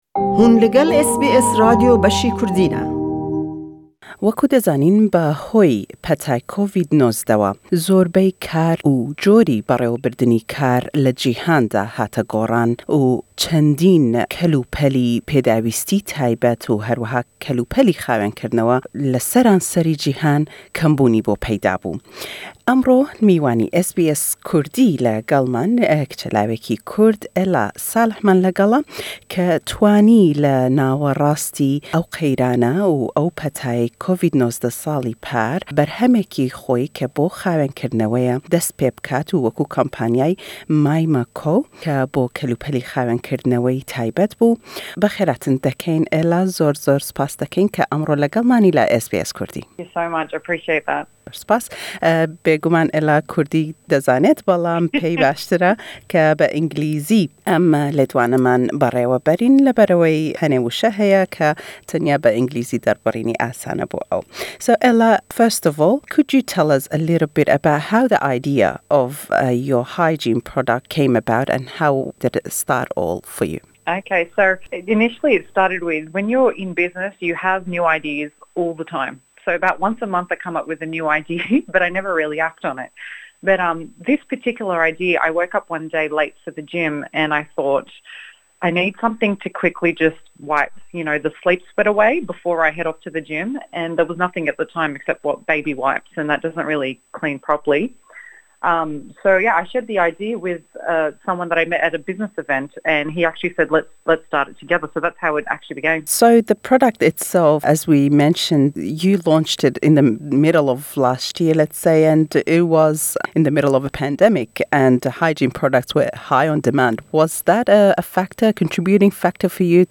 Em hevpeyvîne be zimanî Înglîzîye, pêşgutin be Kurdîye.